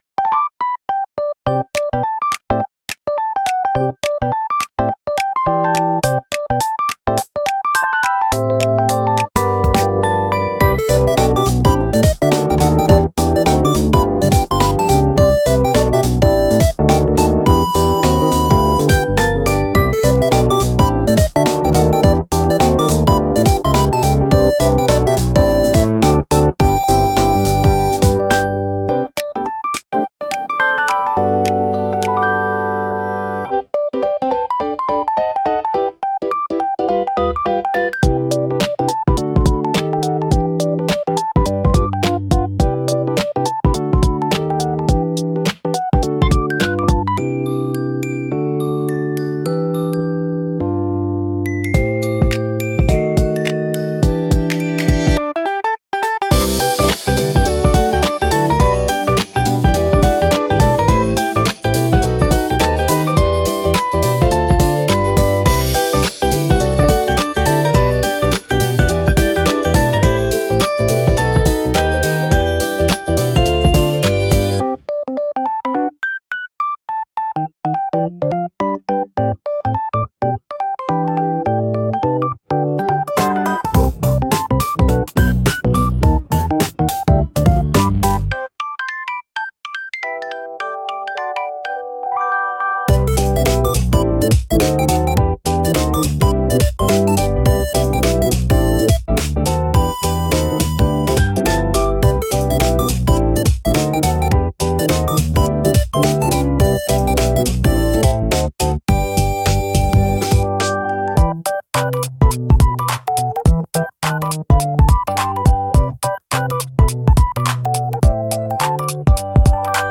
SUNO AI を使用して制作しています
かわいいピコピコBGM